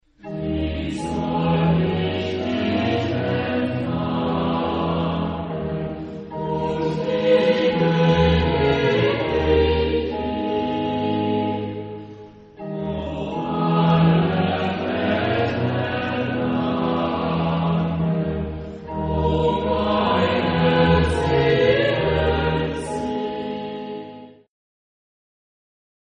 Hassler, Hans Leo (1564-1612) [ Germany ]
Arr.: Bach, Johann Sebastian (1685-1750) [ Germany ]
Genre-Style-Form: Sacred ; Baroque ; Chorale
Type of Choir: SATB  (4 mixed voices )
Instrumentation: Chamber orchestra